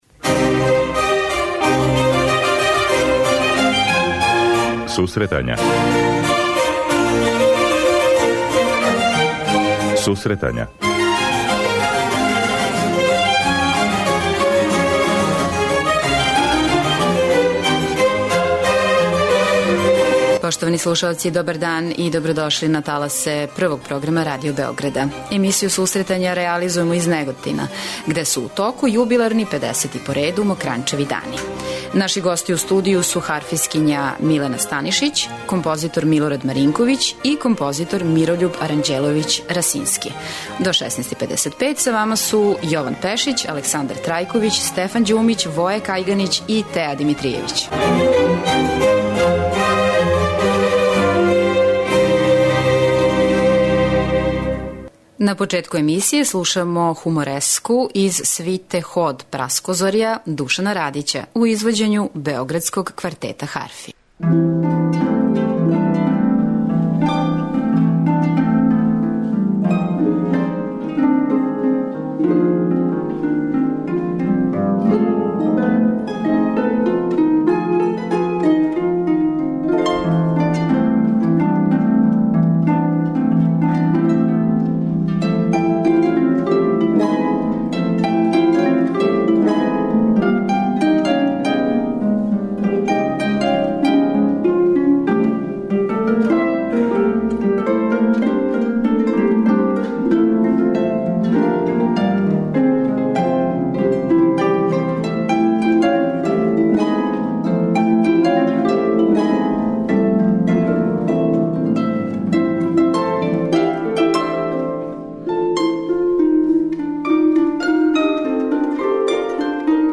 Емисију реализујемо из Неготина где су у току 50. "Мокрањчеви дани".